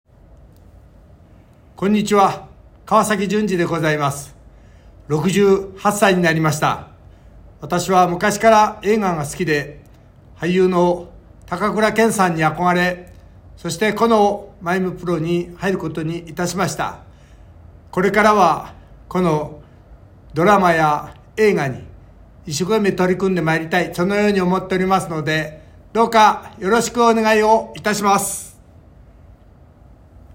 年齢 1954年⽣まれ（70歳） ⾝⻑・体重 168cm・65kg サイズ B/90 W/87 H/92 S/25 出⾝地 石川県 血液型・利き⼿ Ｏ型・右手 趣味 スポーツ観戦 特技 ボクシング 資格・免許 普通自動車運転免許（MT） ボイスサンプル ボイス１